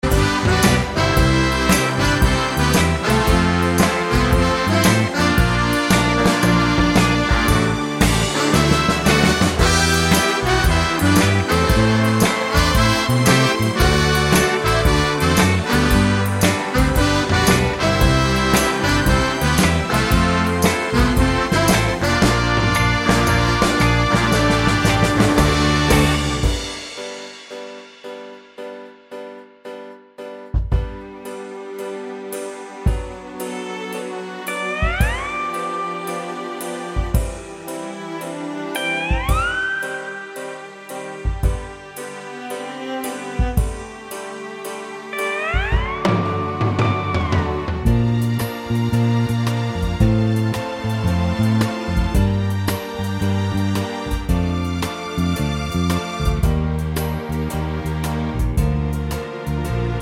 no Backing Vocals Crooners 3:14 Buy £1.50